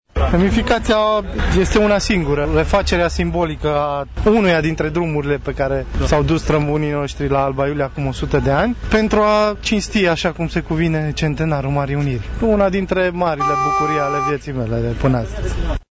Prefectul de Covasna, Sebastian Cucu: